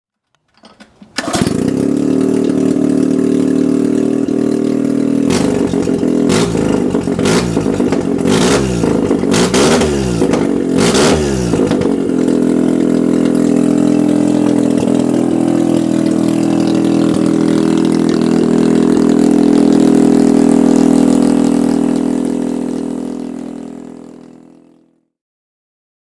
Syvaro (Savkel) SP-440 Einscheiben-Wankelmotor mit 440 cm3 und 30 PS